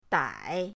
dai3.mp3